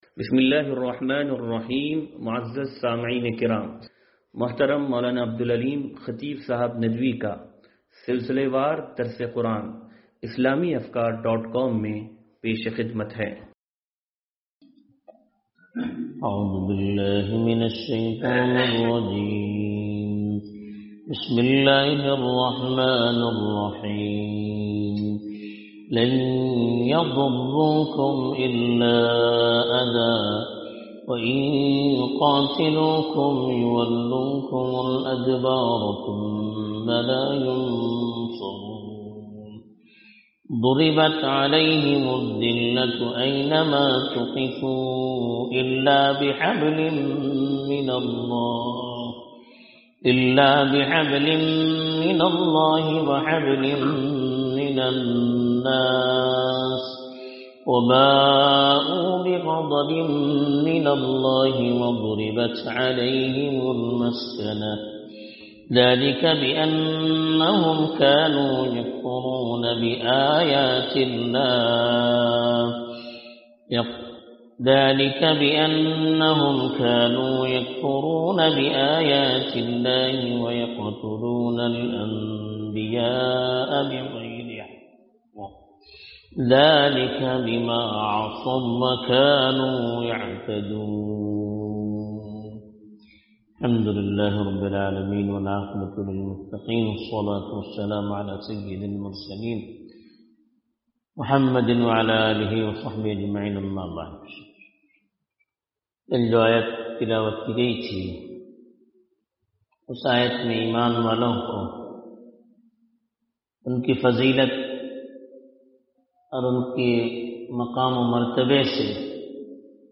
درس قرآن نمبر 0276